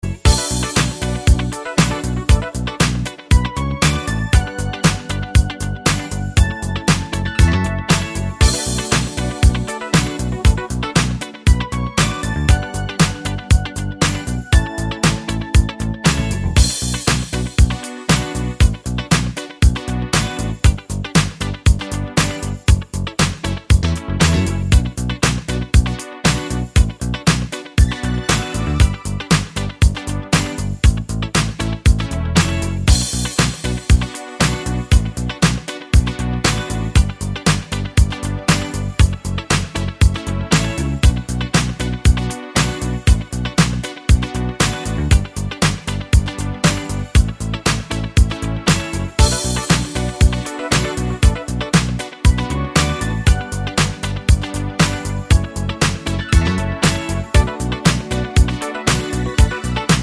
Two Semitones Down Disco 3:40 Buy £1.50